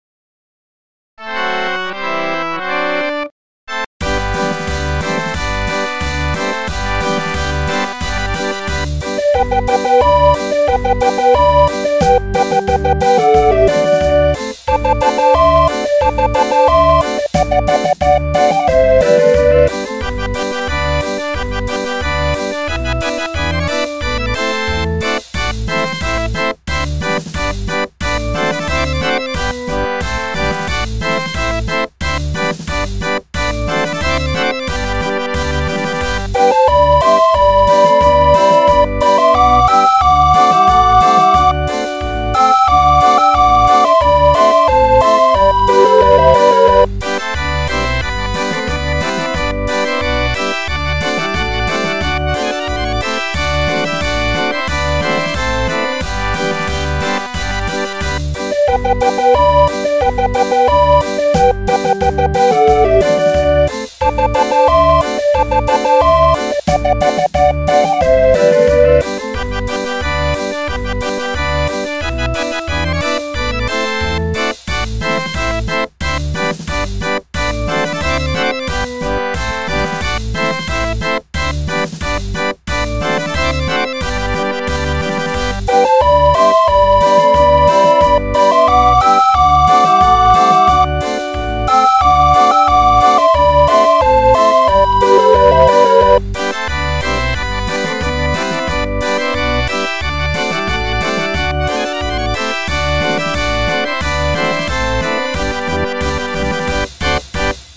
Music rolls, music books and accessoires for barrel organs.